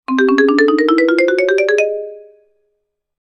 Cartoon Ladder Climb Low Marimba Sound
Description: Cartoon ladder climb low marimba sound. An ascending marimba melody clearly follows a character’s footsteps while climbing stairs or a ladder. This playful cartoon sound effect works perfectly for animations, games, videos, and humorous scenes.
Genres: Sound Effects
Cartoon-ladder-climb-low-marimba-sound.mp3